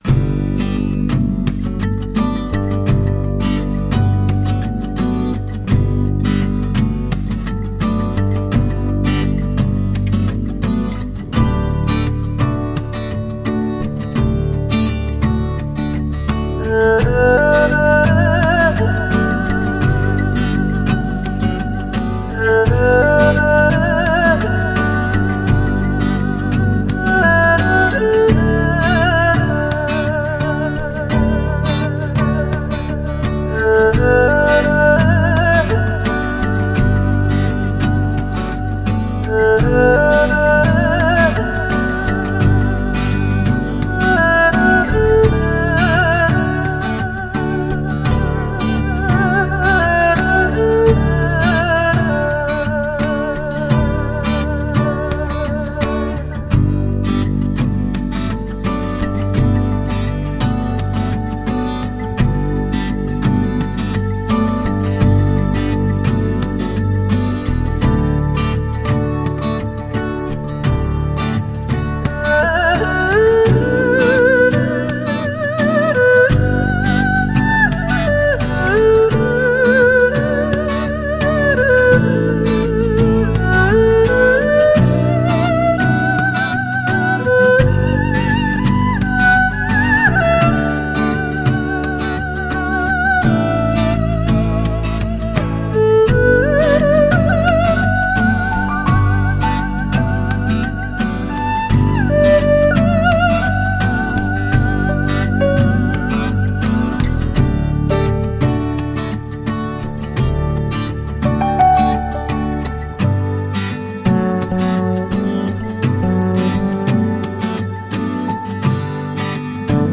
ETHNO, LOUNGE, CHILLOUT, AMBIENT, DOWNTEMPO, NEW AGE